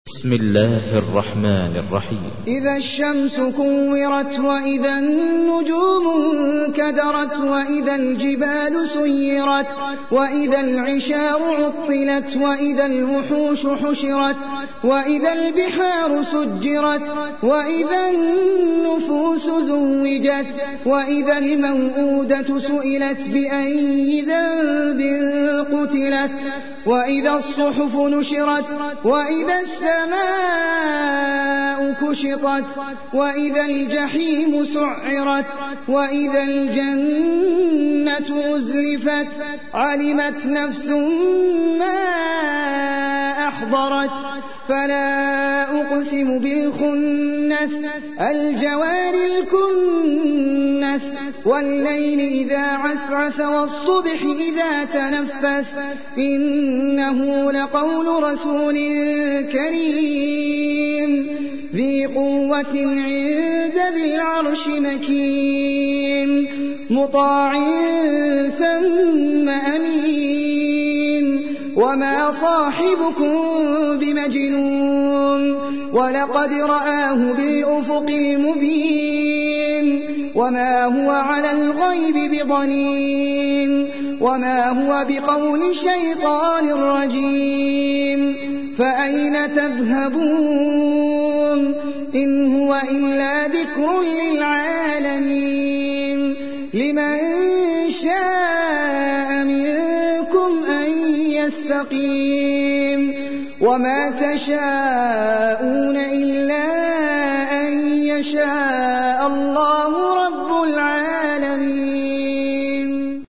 تحميل : 81. سورة التكوير / القارئ احمد العجمي / القرآن الكريم / موقع يا حسين